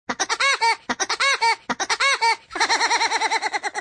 Woodpecker's laugh.wav